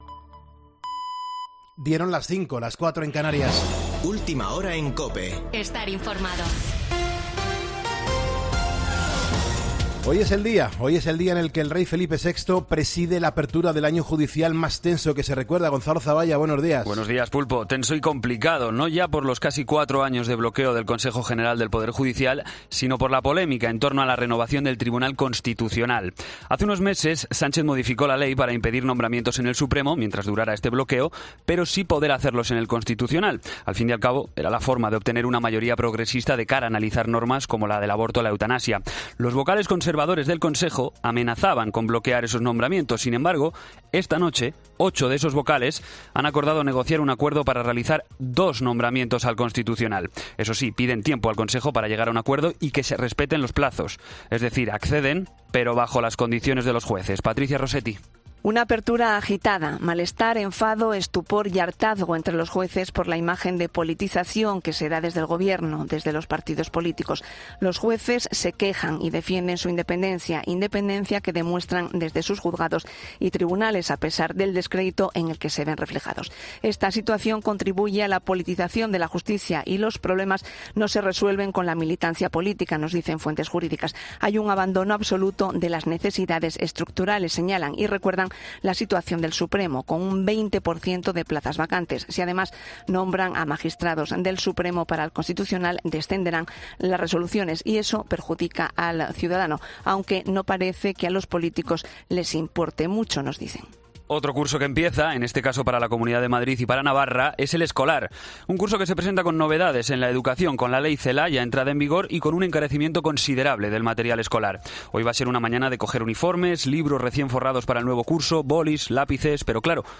Boletín de noticias de COPE del 7 de septiembre de 2022 a las 05:00 horas
AUDIO: Actualización de noticias Herrera en COPE